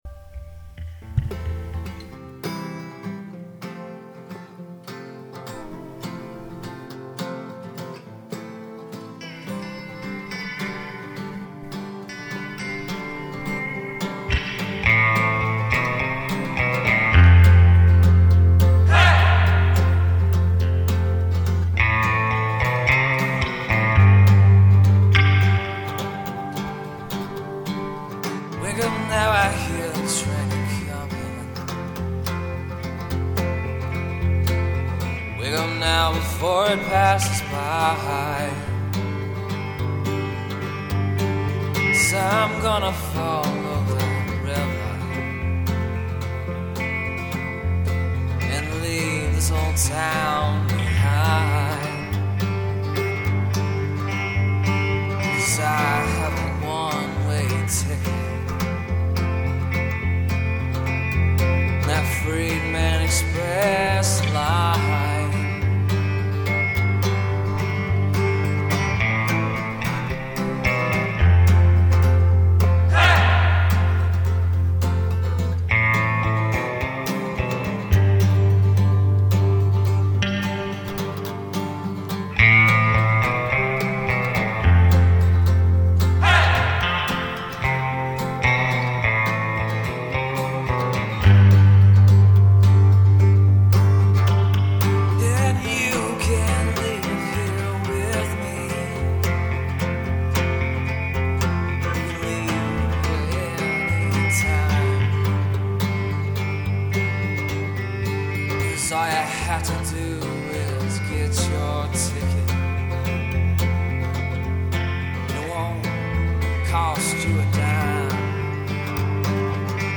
Country
Folk